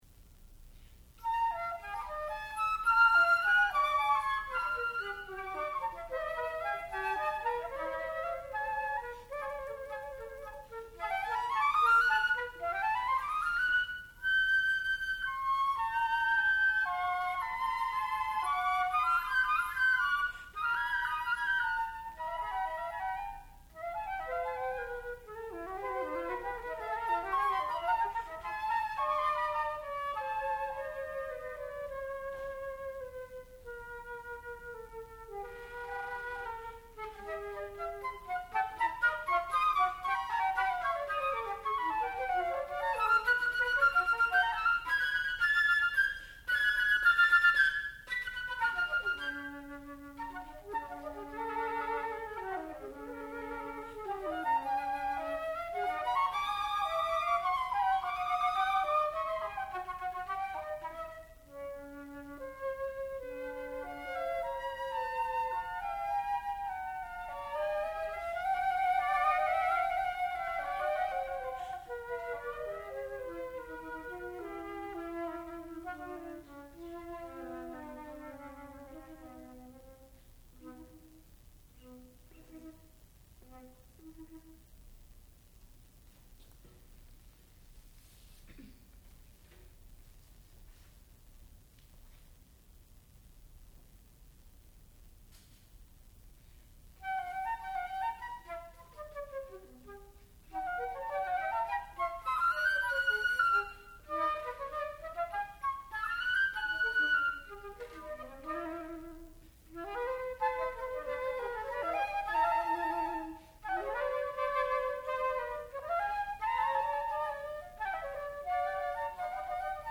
sound recording-musical
classical music
flute
Graduate Recital